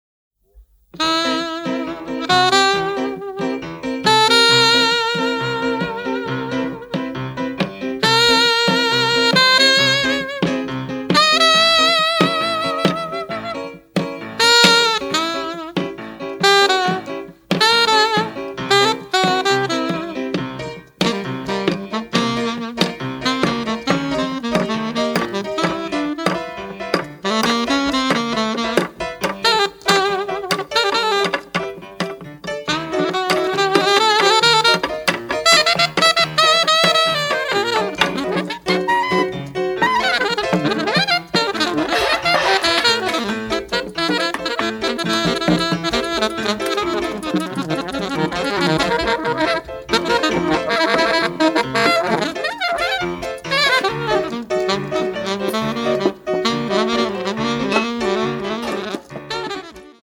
これまでカセットテープのみでの販売となっておりましたが、デジタルリマスターを施した形でのＣＤ化となりました！“
全体を貫く葬送歌のような物悲しい叙情性と、ジャズの過激さが同居しているサウンドがとにかく絶品です！